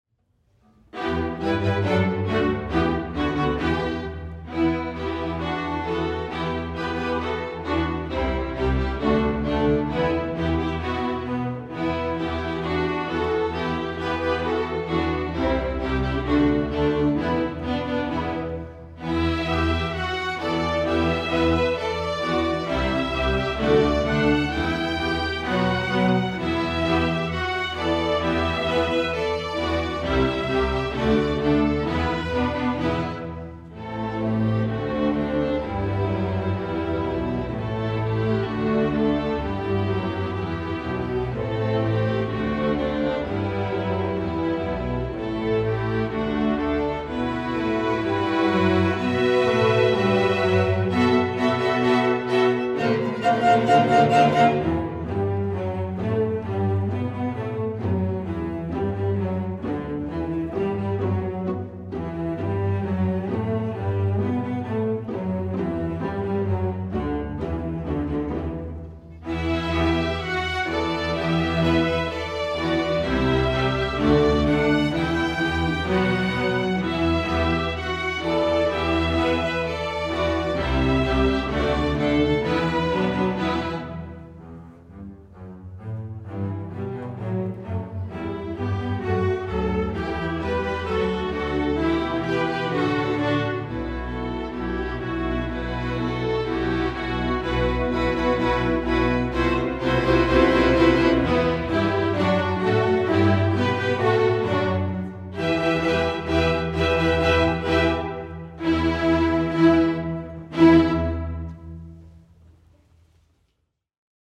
Driving rhythms and fun harmonies
String Orchestra